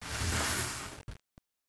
wood_slide.wav